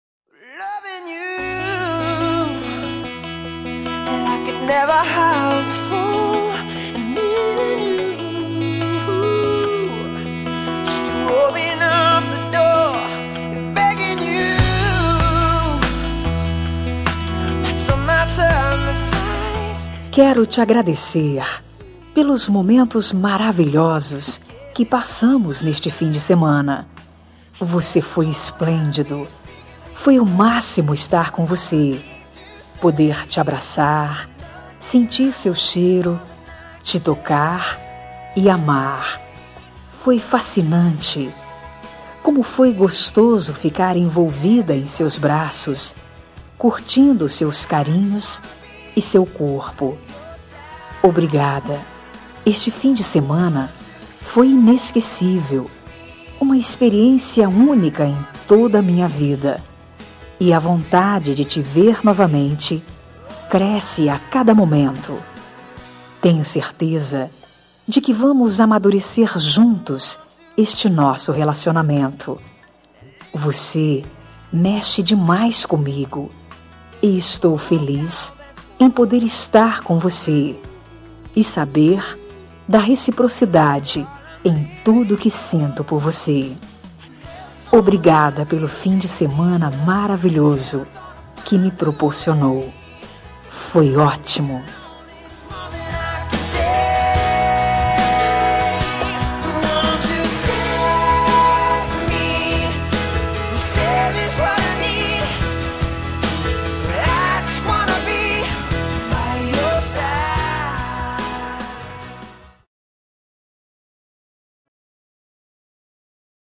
Telemensagem de Agradecimento – Pelo Fim de Semana- Voz Feminina – Cód: 06